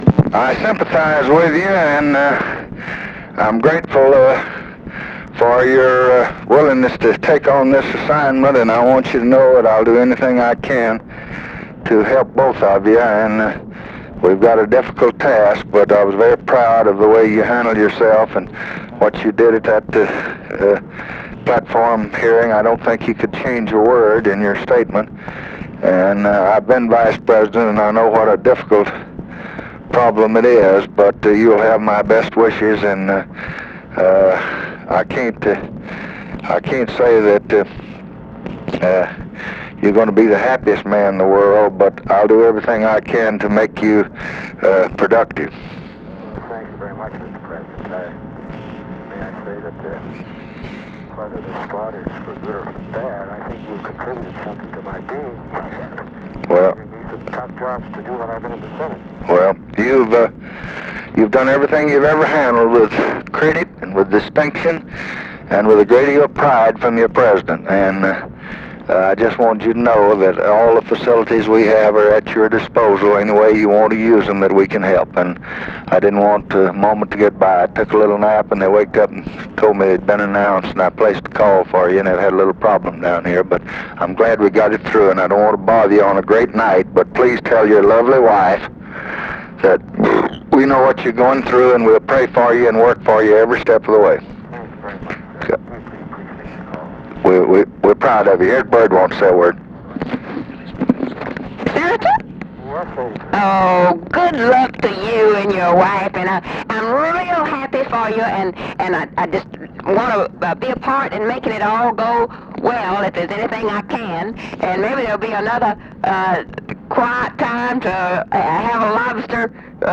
Conversation with EDMUND MUSKIE and LADY BIRD JOHNSON, August 29, 1968
Secret White House Tapes